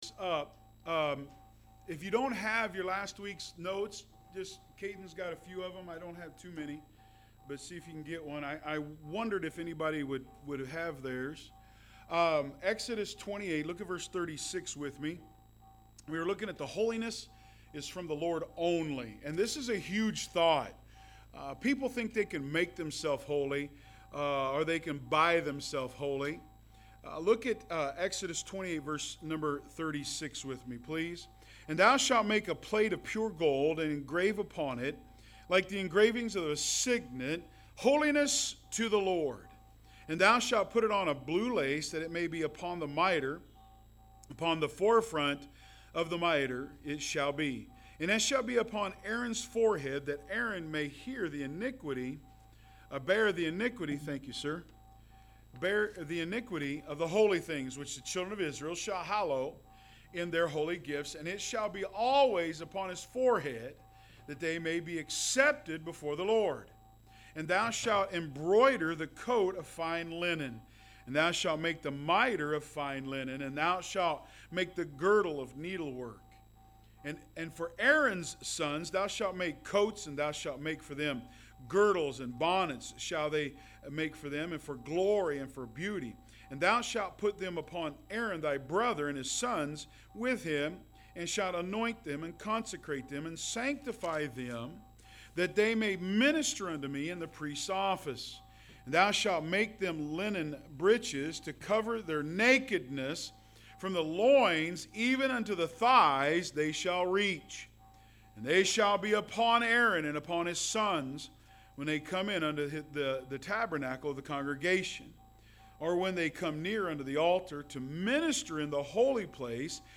Online Sermons – Walker Baptist Church